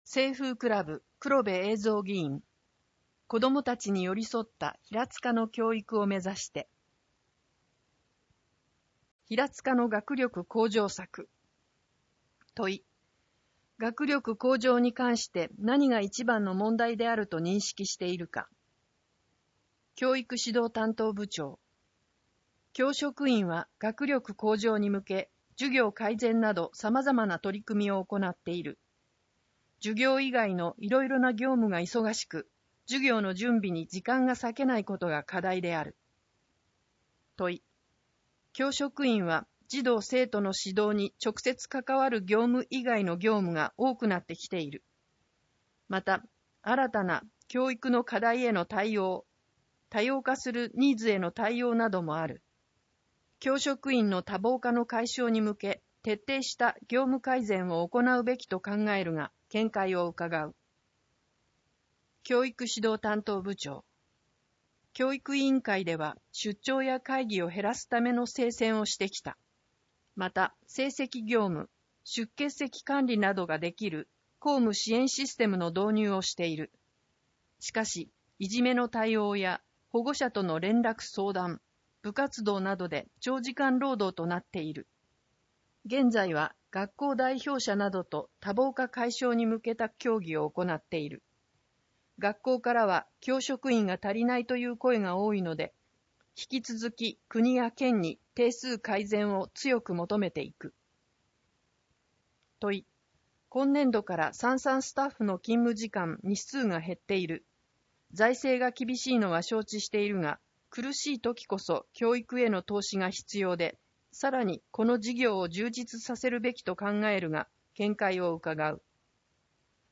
平塚市議会では目の不自由な方に、ひらつか議会だよりを音声化した「声の議会だより」と、掲載記事を抜粋した「点字版議会だより」をご用意しています。
「声の議会だより」は平塚市社会福祉協議会と平塚音訳赤十字奉仕団の協力により作成しています。